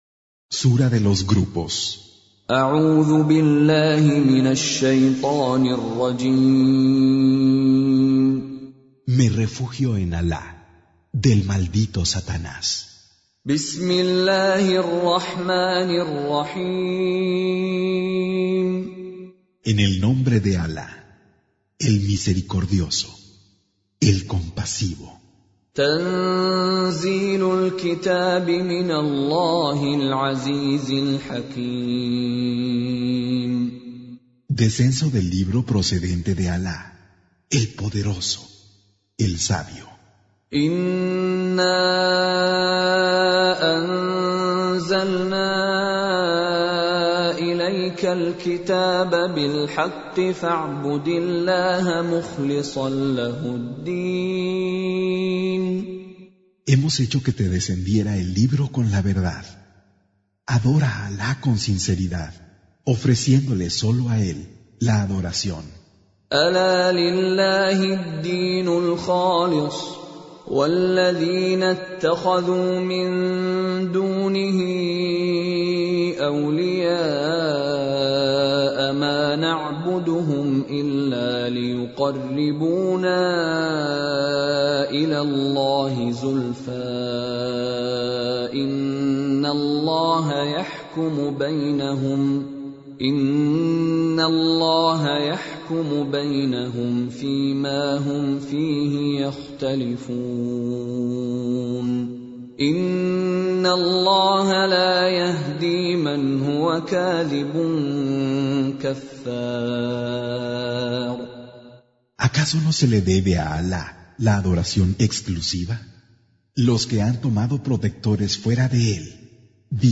Traducción al español del Sagrado Corán - Con Reciter Mishary Alafasi
Surah Sequence تتابع السورة Download Surah حمّل السورة Reciting Mutarjamah Translation Audio for 39.